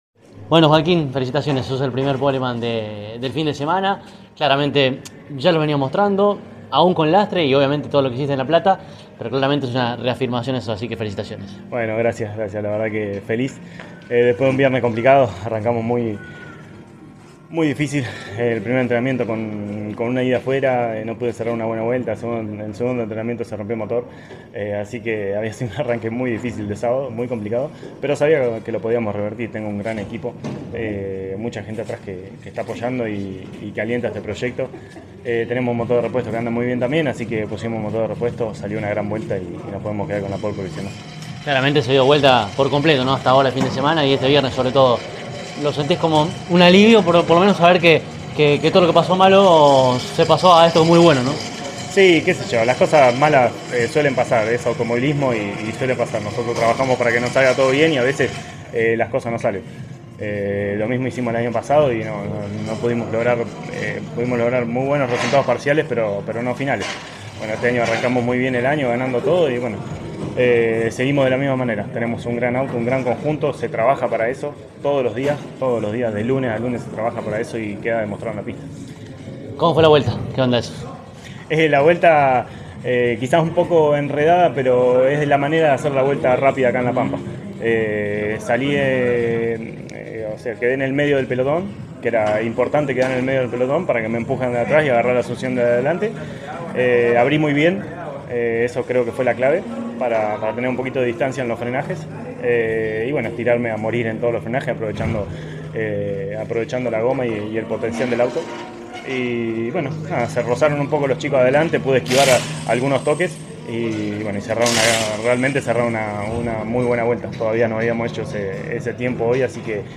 dialogando con CÓRDOBA COMPETICIÓN: